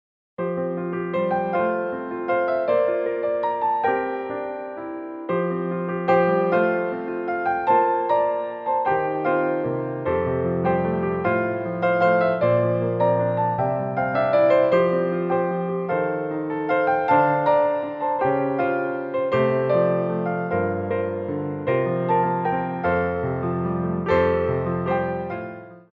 Pirouettes
3/4 (16x8)